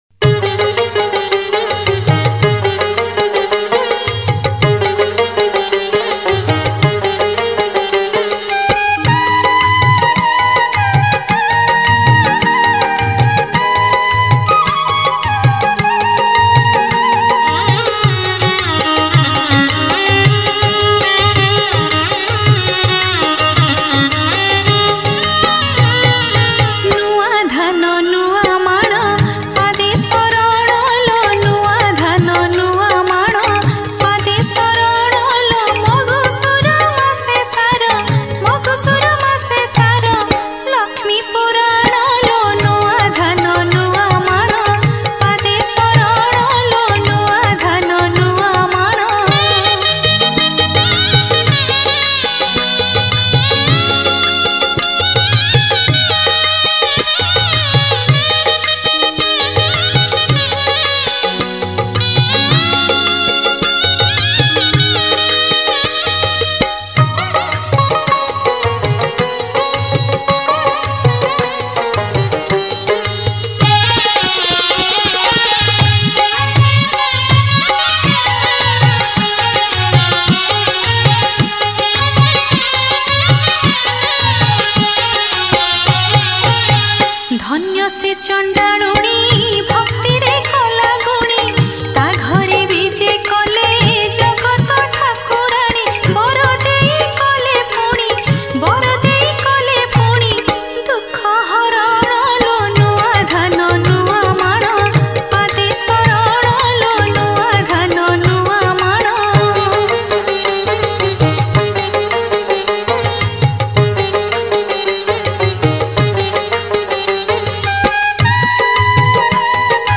Manabasa Gurubara Bhajan